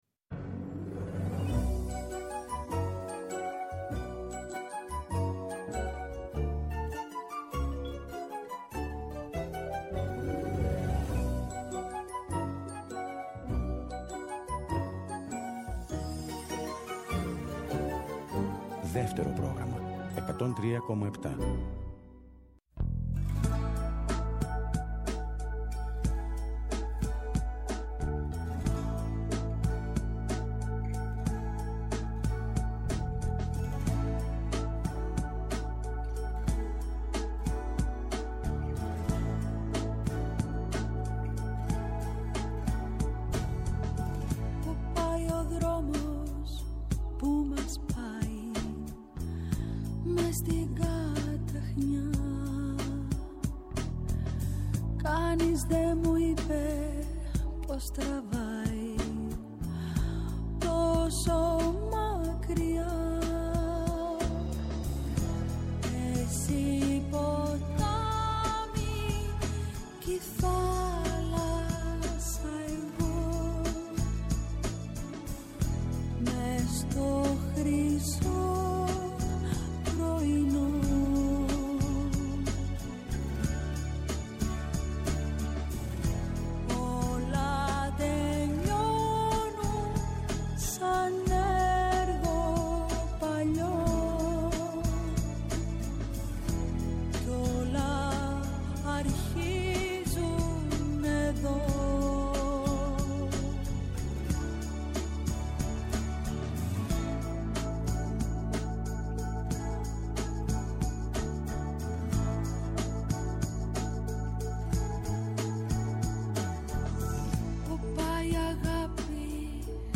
«Άλλη μια μέρα» στον αέρα του Δεύτερου, εκπομπή καλής διάθεσης και μουσικής, για την ώρα που η μέρα φεύγει και η ένταση της μέρας αναζητά την ξεκούραση και τη χαρά της παρέας.